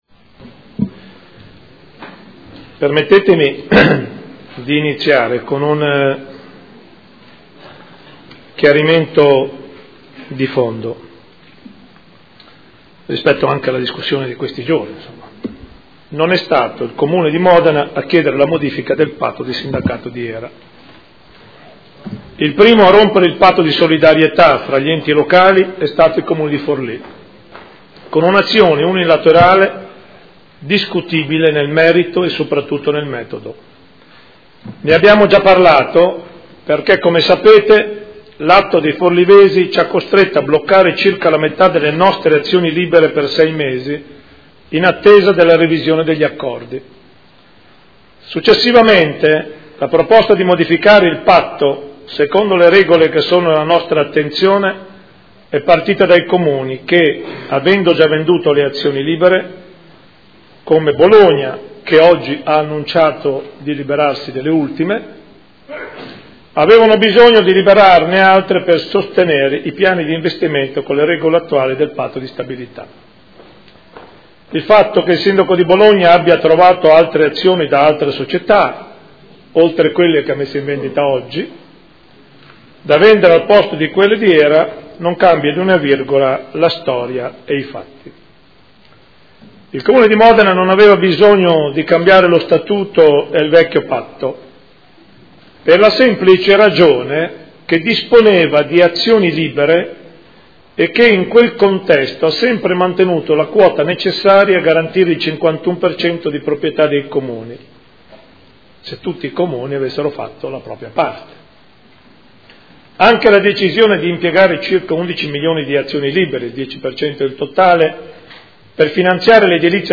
Sindaco — Sito Audio Consiglio Comunale